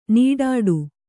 ♪ nīḍāḍu